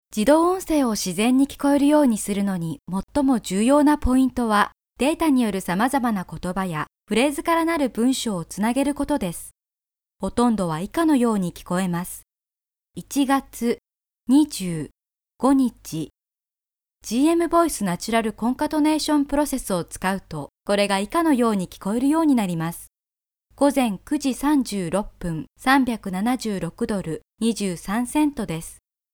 Clear, warm, sincere and friendly Japanese voice with 16 years experiences!
Sprechprobe: Industrie (Muttersprache):